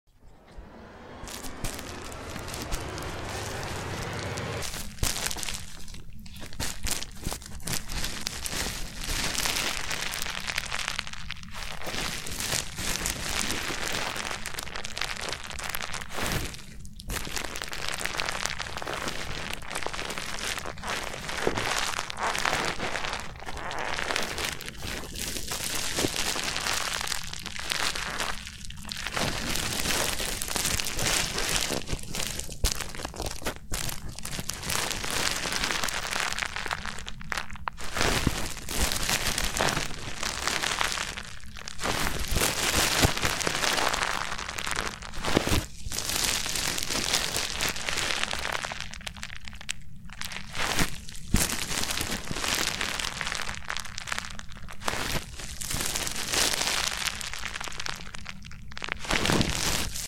ASMR Testing New Mic on sound effects free download
ASMR Testing New Mic on Bubble Wrap | Crisp Popping & Crinkling Sounds
In this ASMR video, I test out a new microphone by using Bubble Wrap, capturing crisp popping and crinkling sounds. The detailed and immersive noises provide a perfect experience for relaxation and tingles.